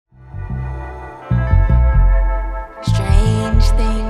where dub tones collide with a melancholic soul narrative.